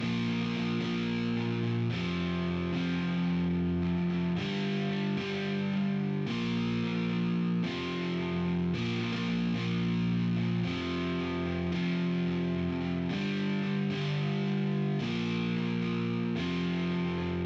Die virtuellen Gitarren von AmpleSound können zwei unterschiedliche Samples gleichzeitig abfeuern (L/R). Diese Samples strahlen aber von außen in die Mitte, sodass der Sound weniger getrennt klingt...